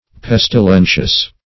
Pestilentious \Pes`ti*len"tious\, a.